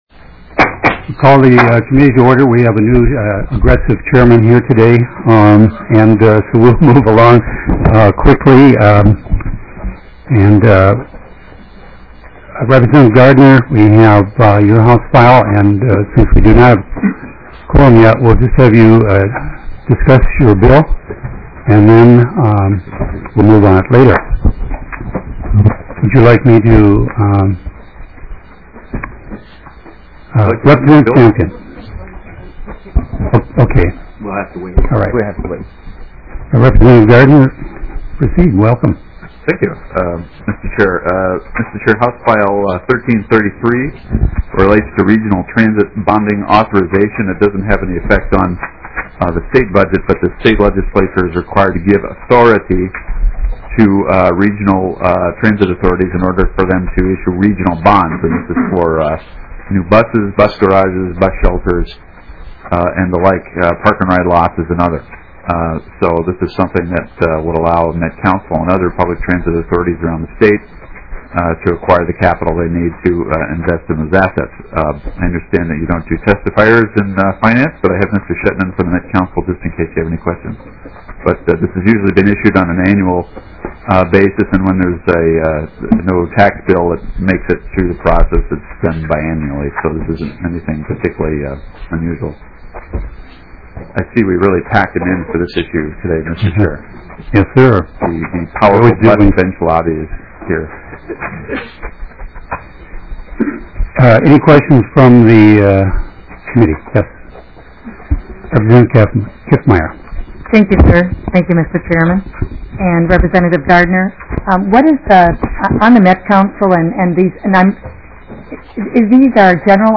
Finance 13th MEETING - Minnesota House of Representatives
Gavel. 02:30 - HF1333 (Gardner) Metropolitan area transit and paratransit capital expenditures additional funding provided, and certain obligations authorized.